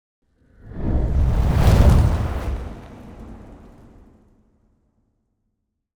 fire_start.wav